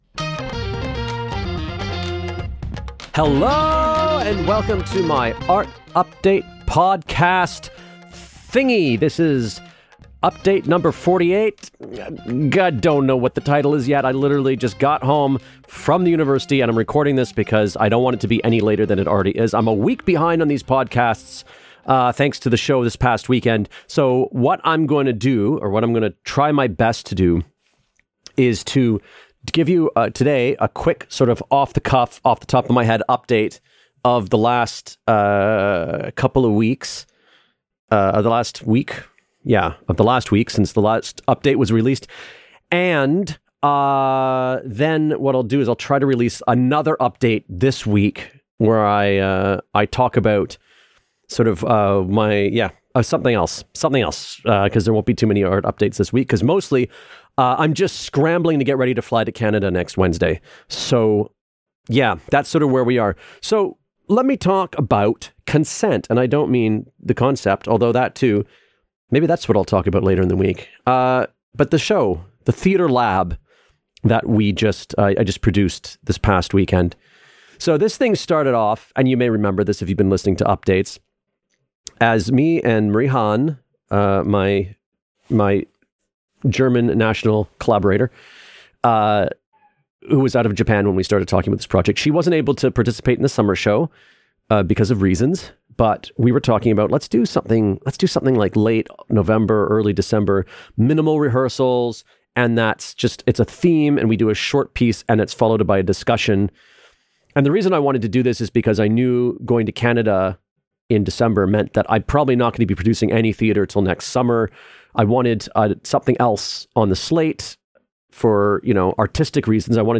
Another non-scripted update (and late again, sorry-- as I mention in the update, I'll try to record a second one before Friday). In this one, I talk about Consent, the theatre lab I just finished this past weekend.